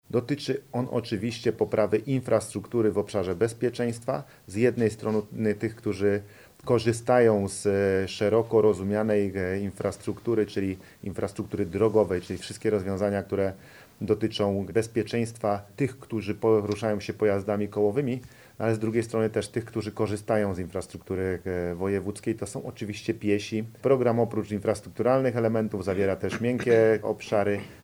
– Po programach „Dolny Śląsk na Dobrej Drodze” i „Dolny Śląsk na Dobrych Torach” prezentujemy dziś działania skoncentrowane na bezpieczeństwie – mówi Paweł Gancarz, marszałek Województwa Dolnośląskiego.